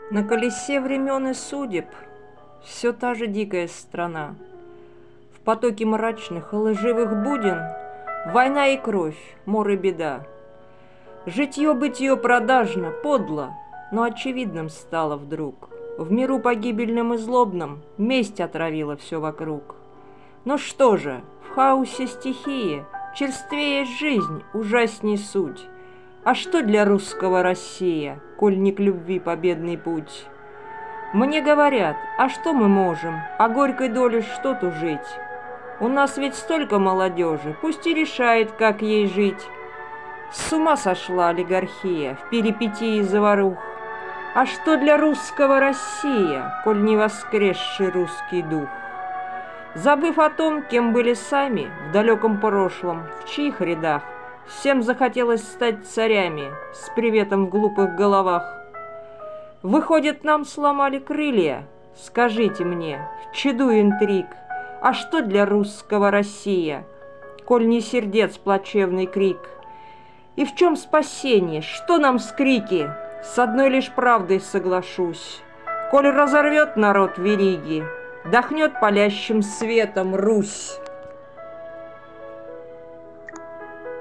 Озвучка автора Музыка классики Эклектика классицизма